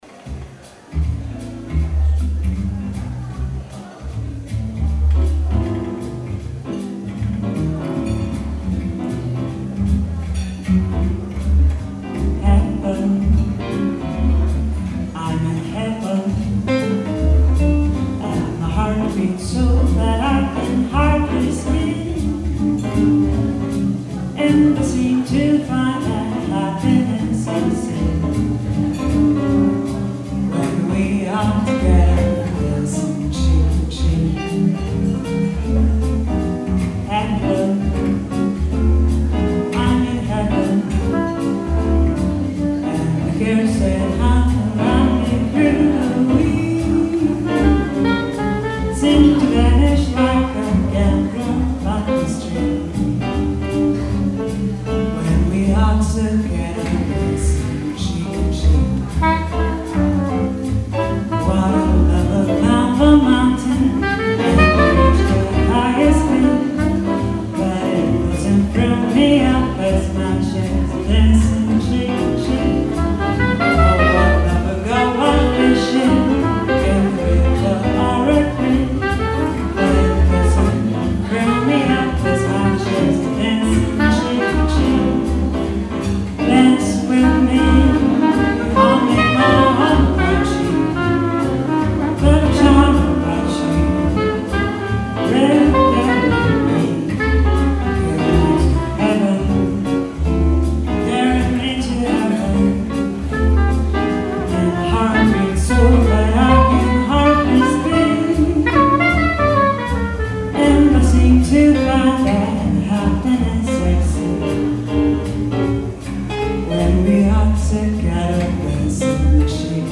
Koncert inauguracyjny Fundacji pt. ,,Nowa Zielonka – Kobietom”